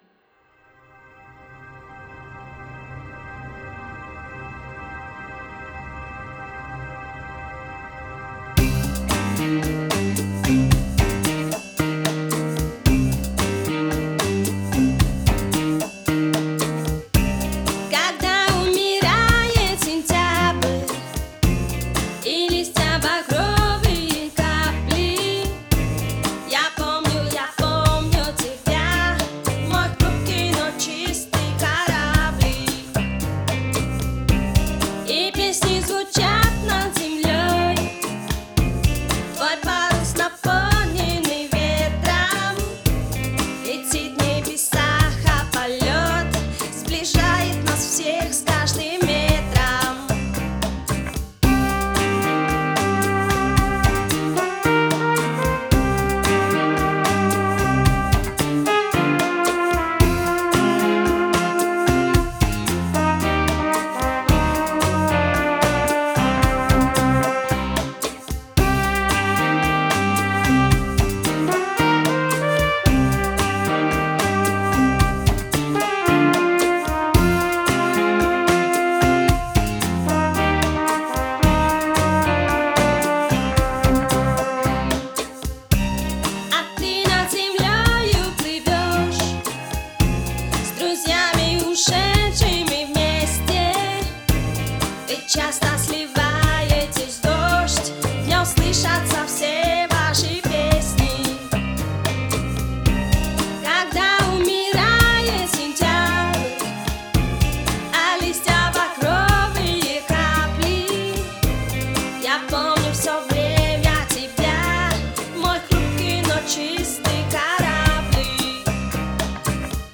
Кораблик (латино)